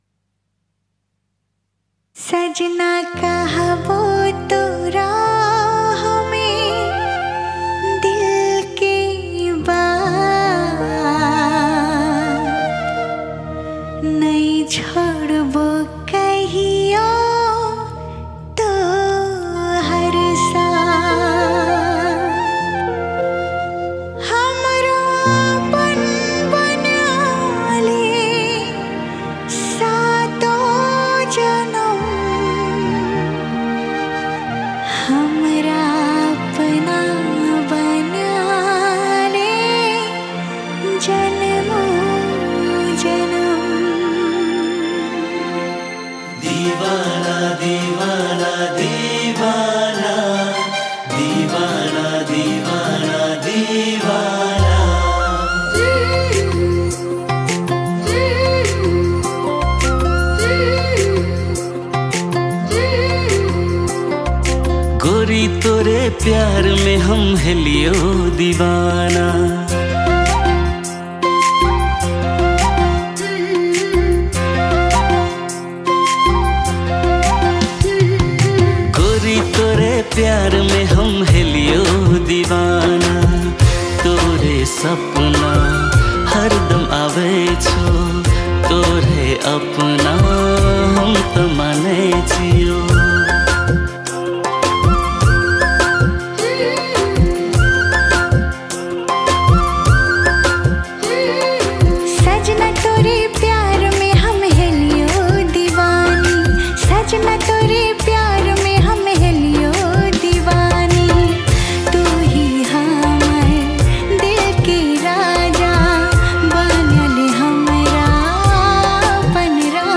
Tharu Romantic Song